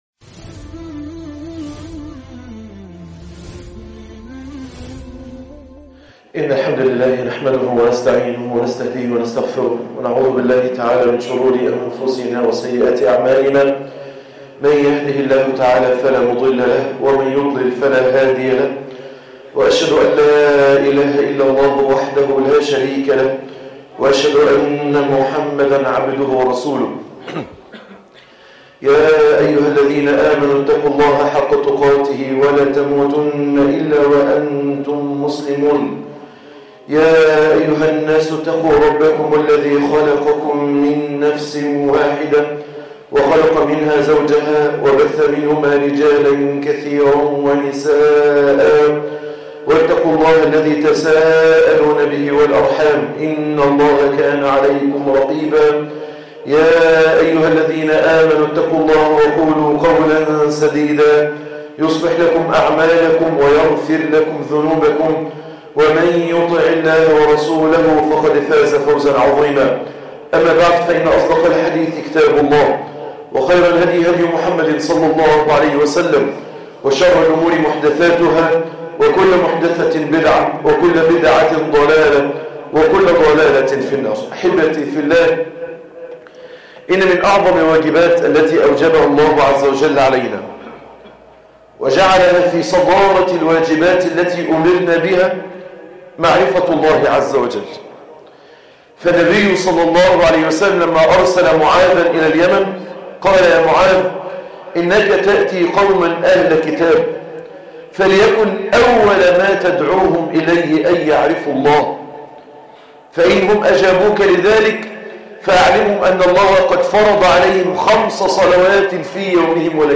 9- حفظ الله لعباده - خطبة الجمعة - مسجد نور الإسلام